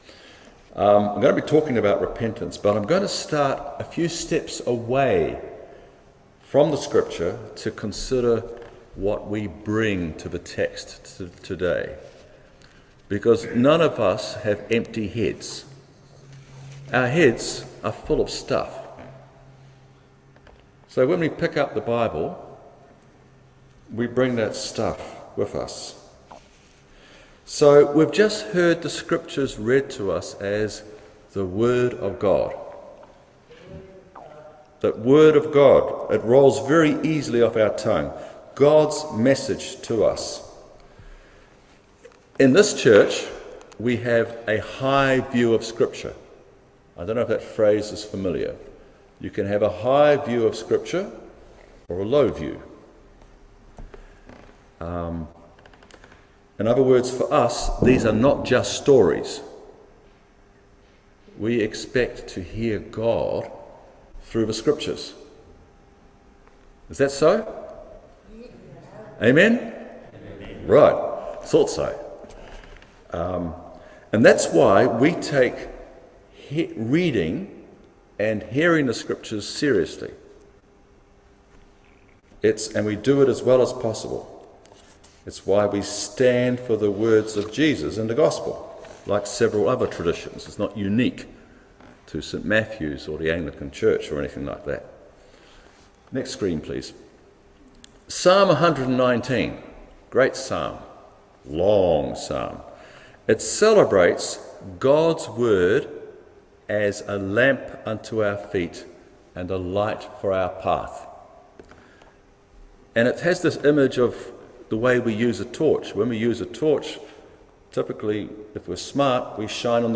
Luke 13:1-9 Service Type: Holy Communion What is your image of God?